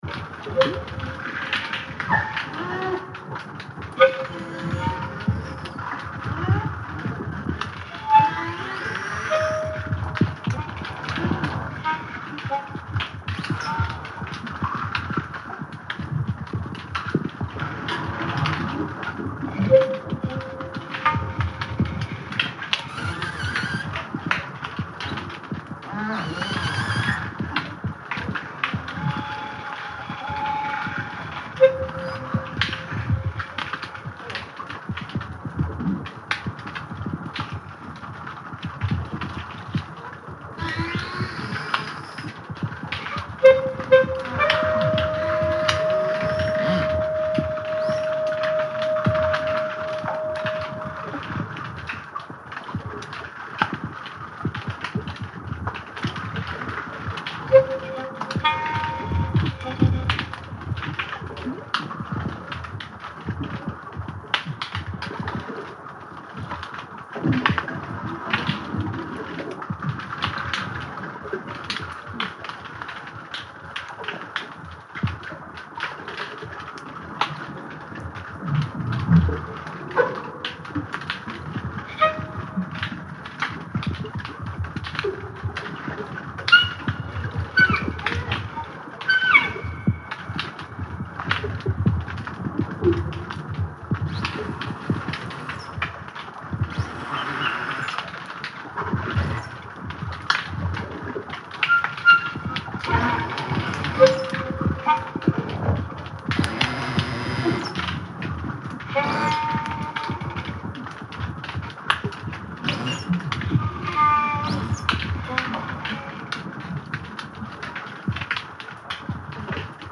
An underwater microphone, called a hydrophone, picks up those sounds along with the underwater sax or clarinet, which has a more resonant, bell-like tone because of the way overtones carry underwater.
First the background, thumping, burbling waves splashing on rocks.
Clicks to find their way, and whistles to signal.
A whale sings, a clarinet rings. The sounds overlap and connect.
listen to the whales sing and the clarinet ring.
Clarinet and Beluga Music (MP3)
clarinet_beluga.mp3